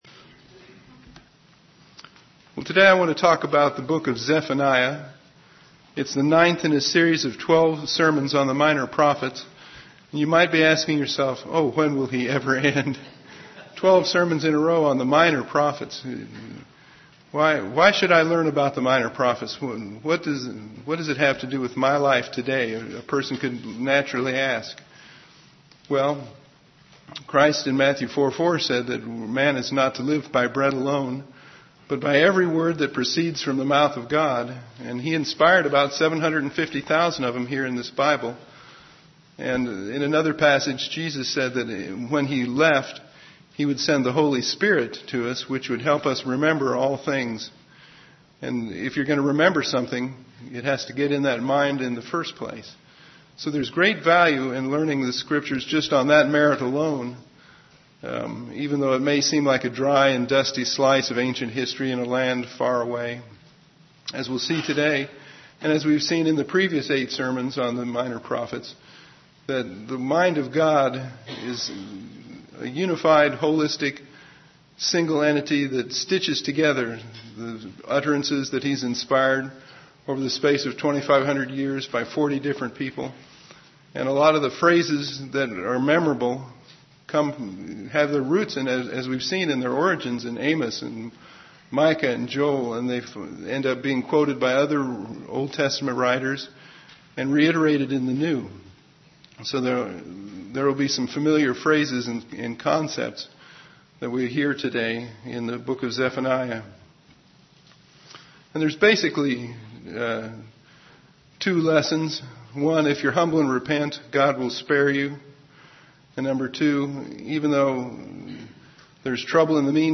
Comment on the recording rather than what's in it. Given in Ft. Wayne, IN